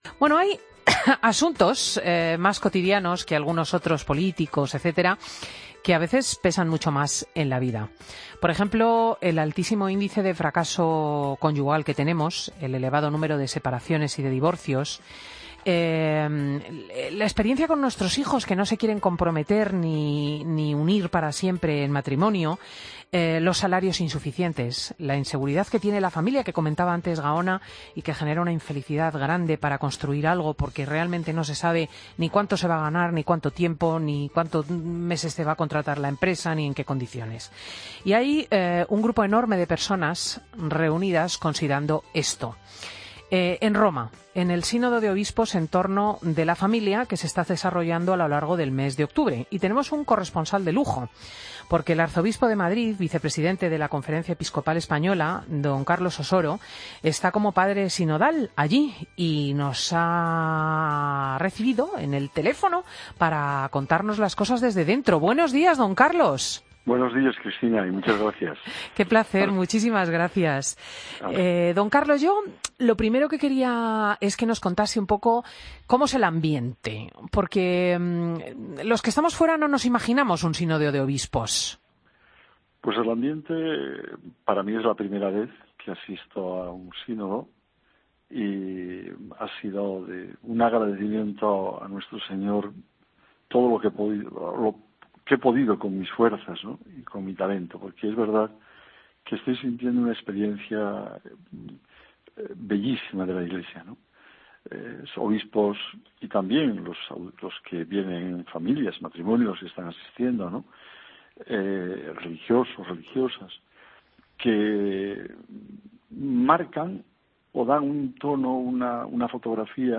AUDIO: Escucha la entrevista a Mons. Carlos Osoro en 'Fin de semana'